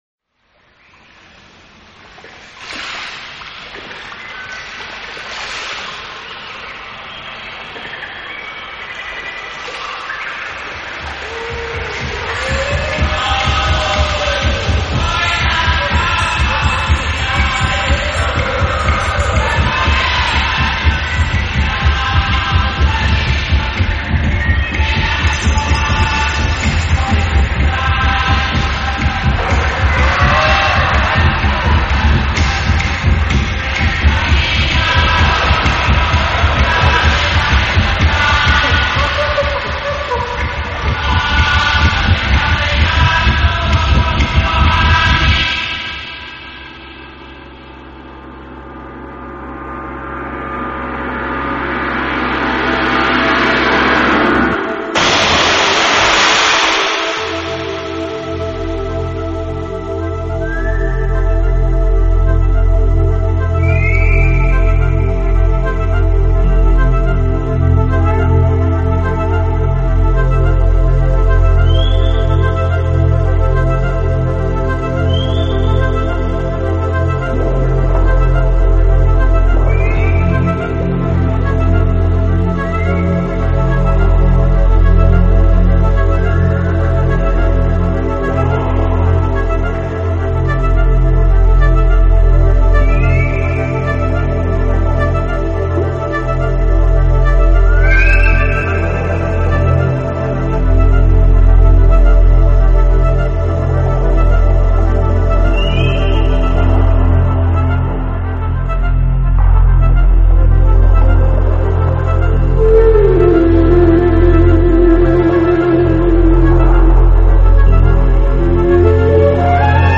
ethno space mix